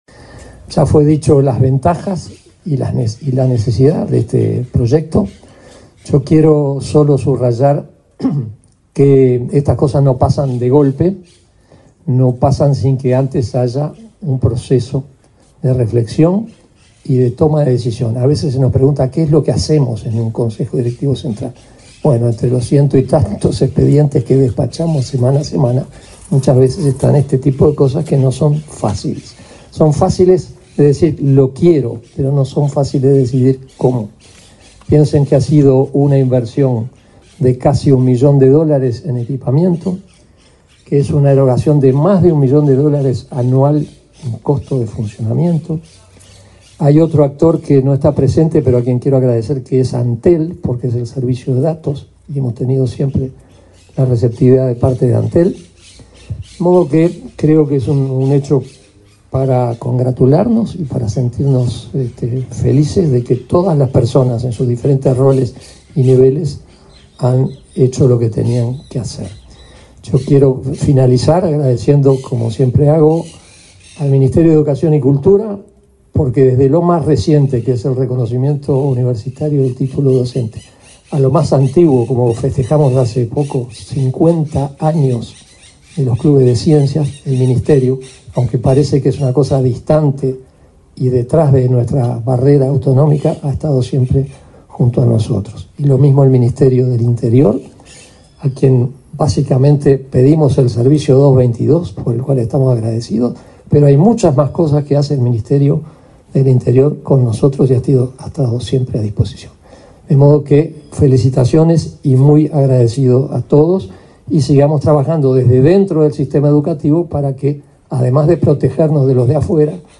Palabras de autoridades en acto de la ANEP
Palabras de autoridades en acto de la ANEP 24/02/2025 Compartir Facebook X Copiar enlace WhatsApp LinkedIn El presidente de la Administración Nacional de Educación Pública (ANEP), Juan Gabito Zóboli; el ministro del Interior, Nicolás Martinelli, y su par de Educación y Cultura, Pablo da Silveira, participaron en la presentación de un sistema de cámaras de videovigilancia, enfocado a mejorar la seguridad en los centros de enseñanza. El acto se realizó en el complejo educativo de Paso de la Arena, en Montevideo.